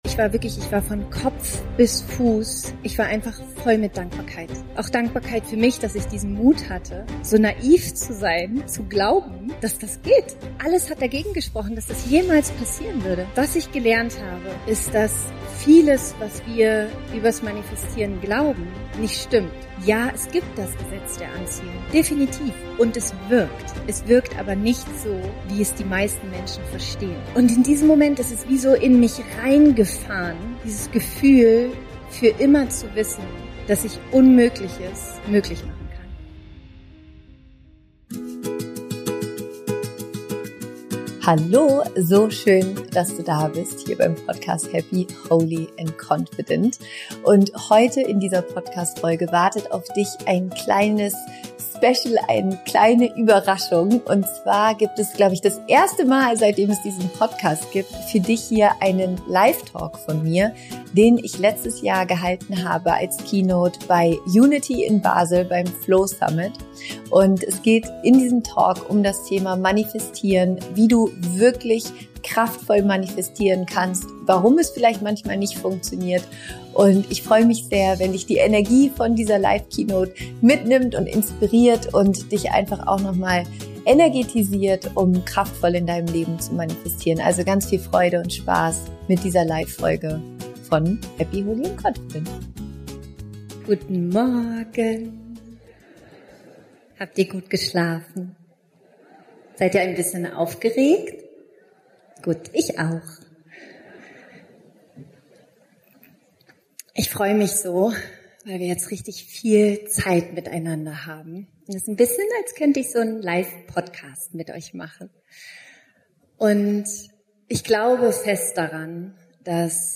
In dieser besonderen Folge nehme ich dich mit zu meinem Live-Auftritt bei Younity in Basel (2024). Es ist eine meiner ehrlichsten und persönlichsten Reden darüber, was Manifestation wirklich ist und was sie nicht ist.